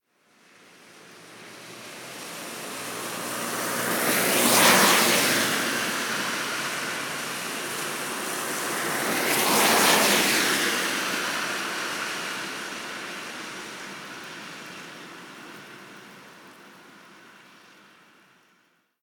Coche pasando sobre charcos 3
charco
coche
Sonidos: Agua
Sonidos: Transportes